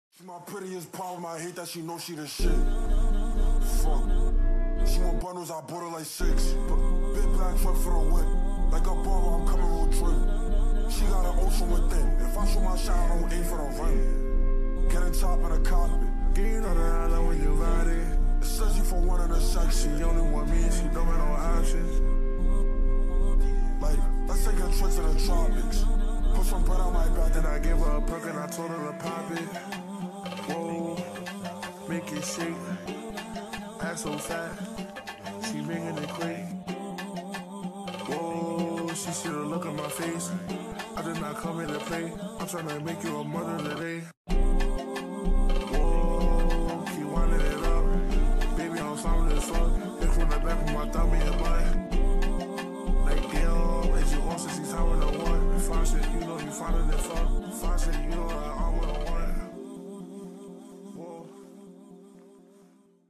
[slowed]